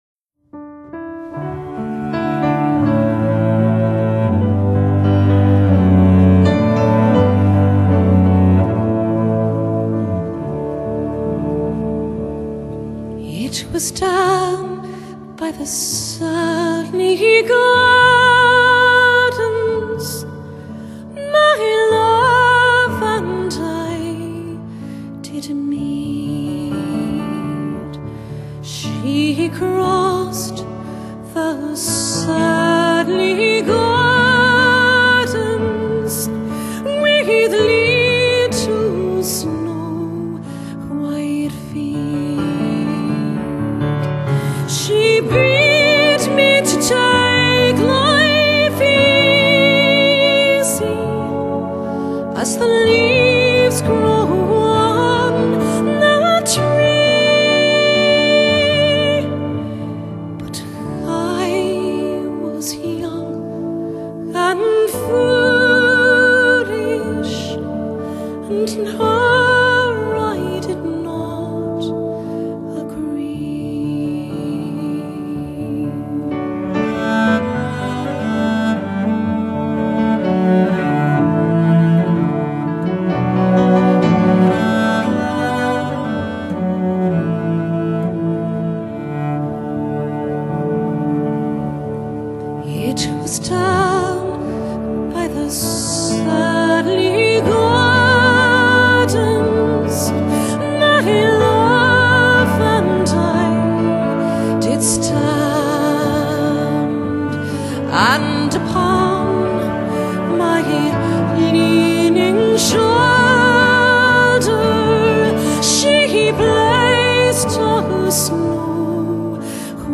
Celtic / Folk | MP3 CBR 320 Kbps | Frontcover | 98 MB
guitars
cello